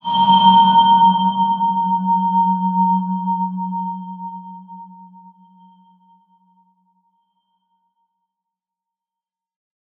X_BasicBells-F#1-mf.wav